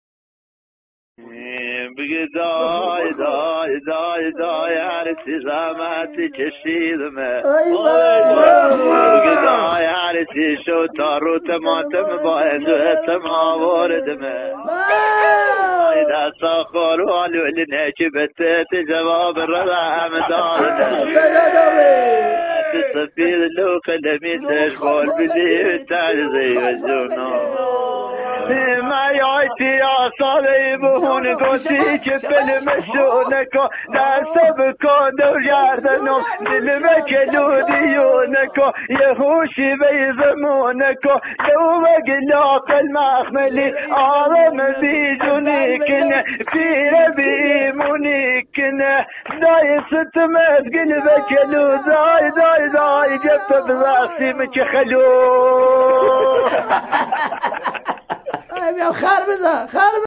ریمیکس
محلی